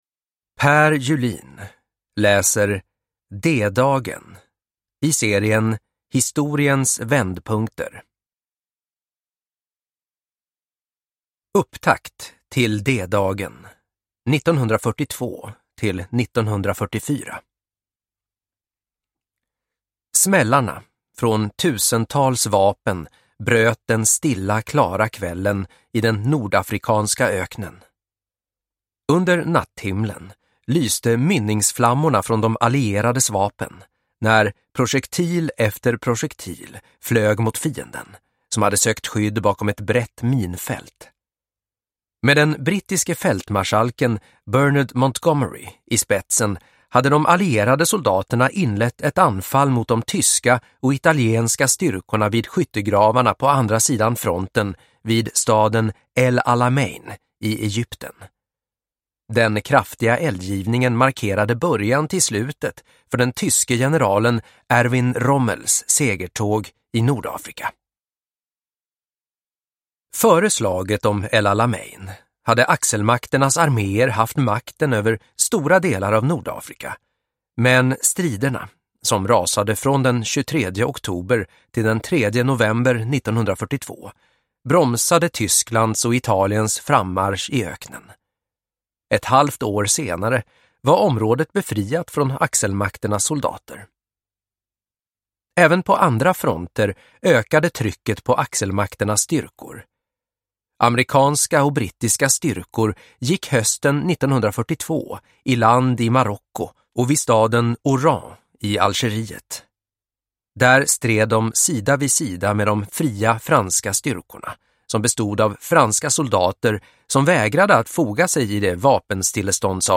D-dagen – Ljudbok – Laddas ner